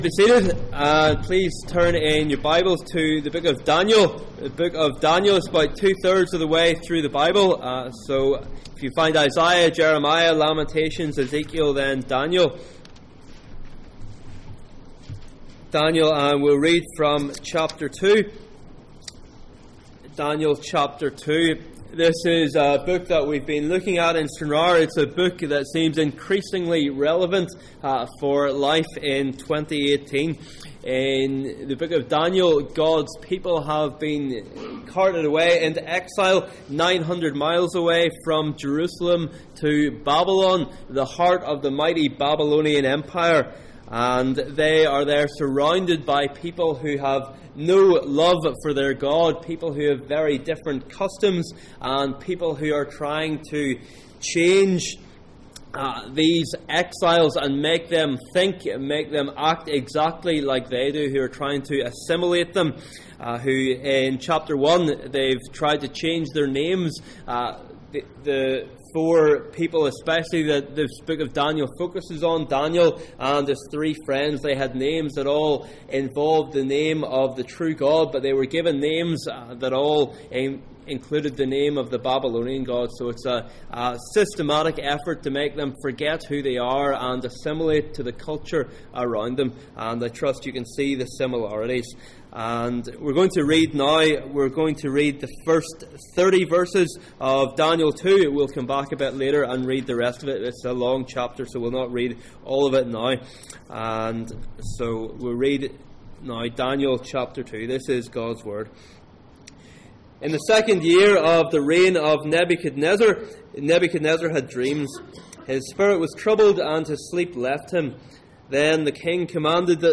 Daniel 2:1-49 Service Type: Sunday Morning %todo_render% « The heart of the matter Do you believe in the resurrection of Jesus?